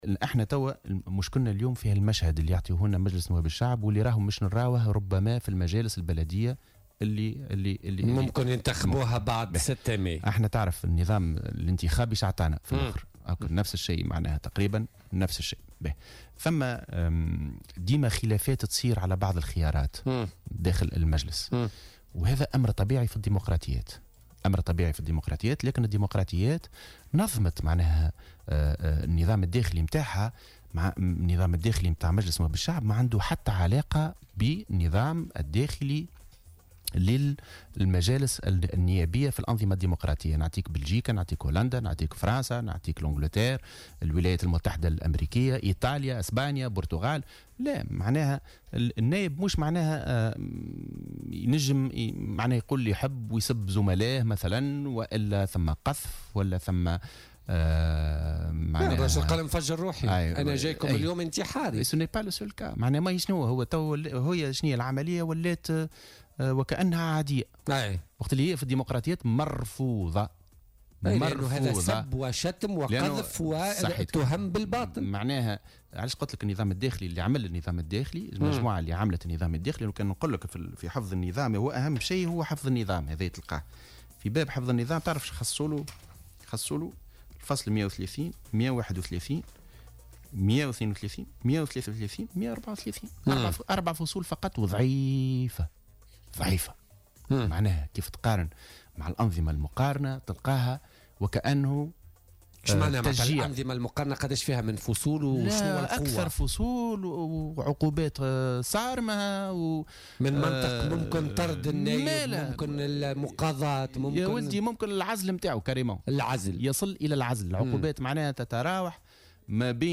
وأوضح في مداخلة له اليوم في برنامج "بوليتيكا" أن هذه الإجراءات التأديبية تبقى ضعيفة مقارنة ببقية الأنظمة الديمقراطية، والتي تصل فيها العقوبات إلى حدّ العزل، وفق تعبيره.